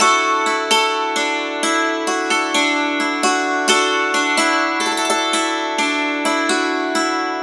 Dulcimer15_129_G.wav